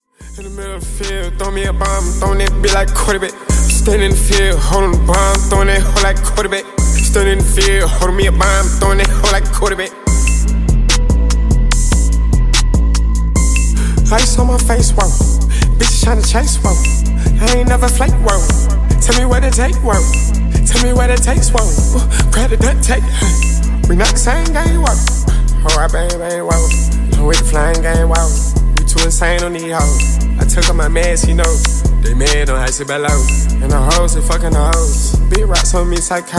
Categoria Rap/Hip Hop